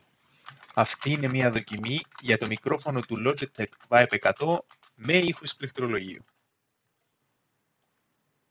• Type: Dual omni-directional MEMS mics with directional beamforming and DSP
Η ποιότητα του κατά τη γνώμη μου είναι μέτρια μέχρι καλή, αναλόγως περιστάσεων.
Ακολουθούν δύο δοκιμές, με και χωρίς ήχο πληκτρολογίου στο background.
With-Keyboard.mp3